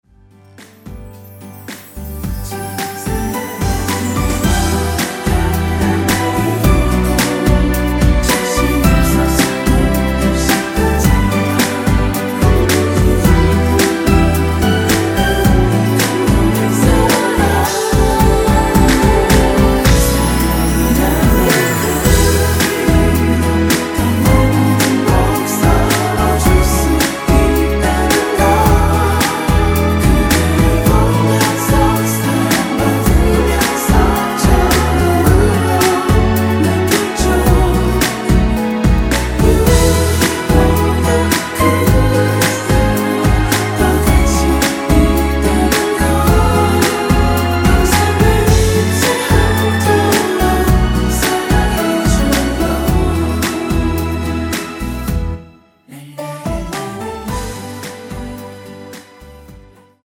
원키 코러스 MR에서 사운드 마커 포함된 전체 미리듣기 가능 하겠습니다.
원키에서(-2)내린 코러스 포함된 MR입니다.
전주가 너무길어 시작 Solo 부분22초 정도 없이 제작 하였으며
엔딩부분이 페이드 아웃이라 엔딩을 만들어 놓았습니다.(미리듣기 참조)
앞부분30초, 뒷부분30초씩 편집해서 올려 드리고 있습니다.